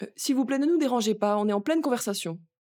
VO_ALL_EVENT_Trop proche de la cible_02.ogg